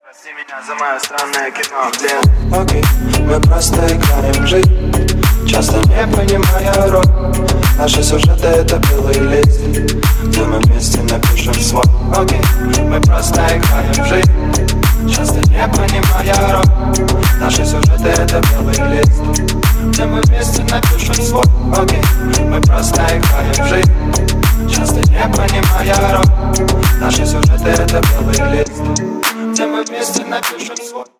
Рэп и Хип Хоп # Танцевальные